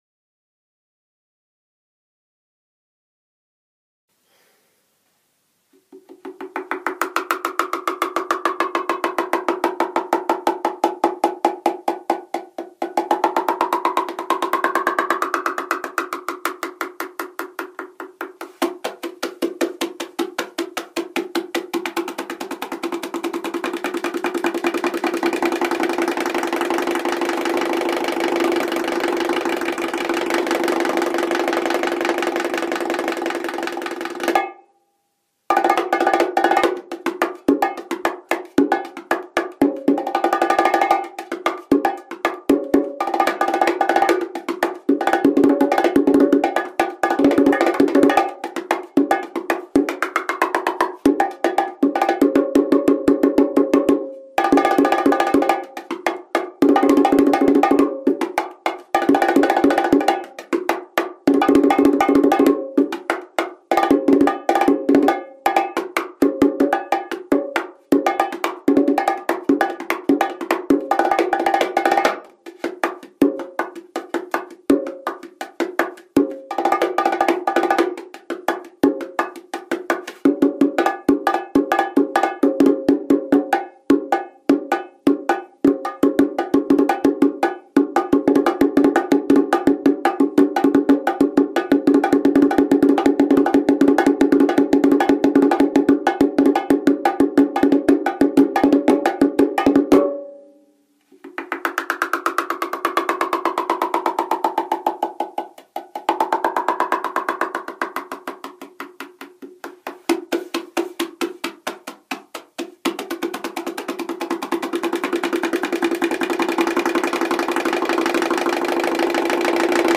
Percusión
BONGOES
bongo_s_solo.mp3